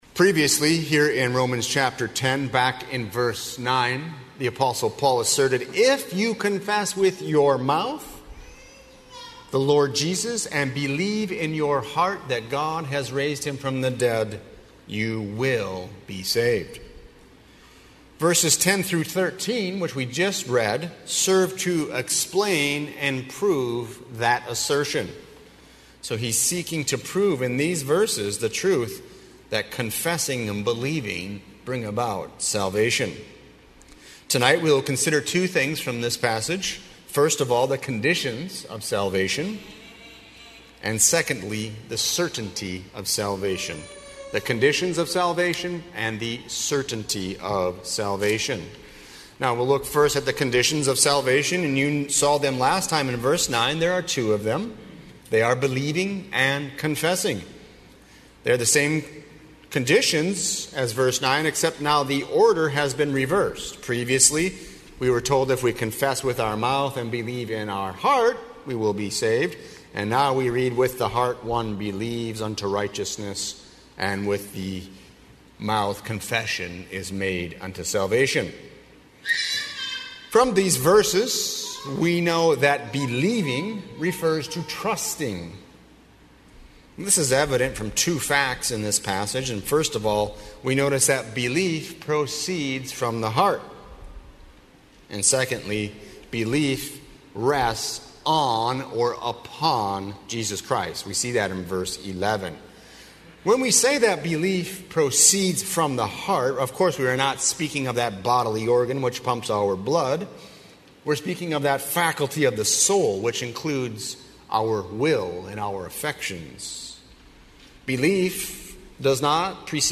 00:00 Download Copy link Sermon Text Believing in Jesus Christ as the eternal Son of God